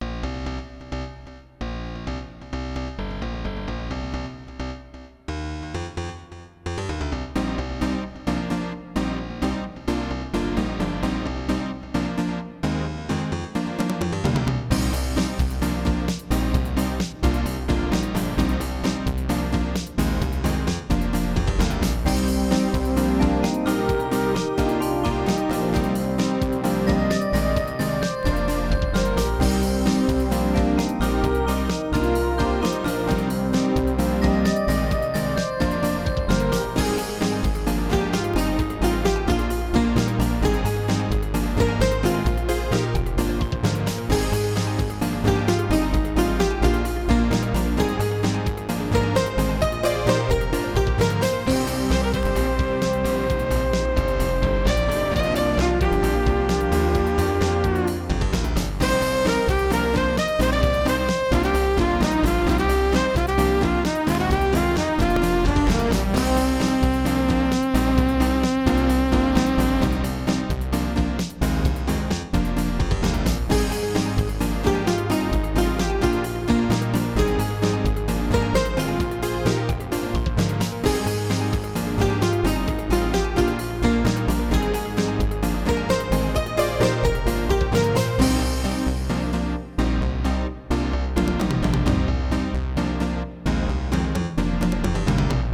Music: midi
Roland LAPC-I
* Some records contain clicks.